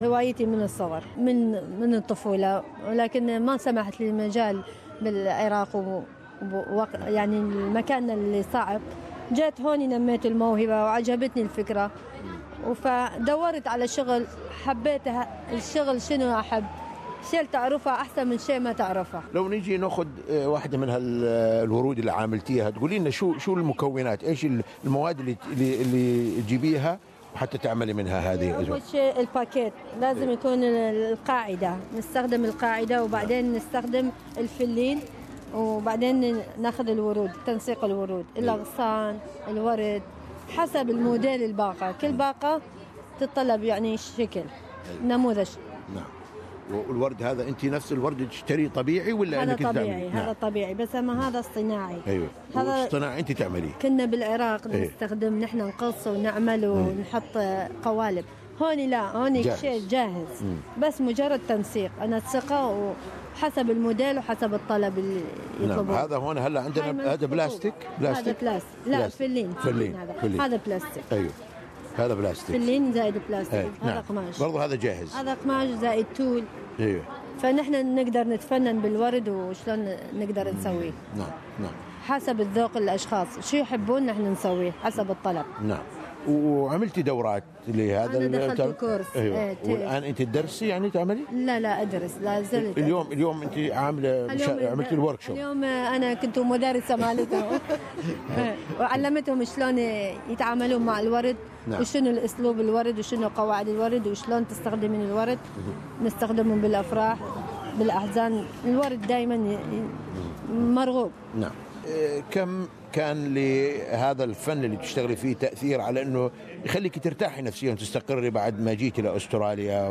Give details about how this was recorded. speaking during a training workshop for flower arranging within the festival "Shanasheel". How this art confers a sense of happiness and hope on it and helps it escape from the problems of life.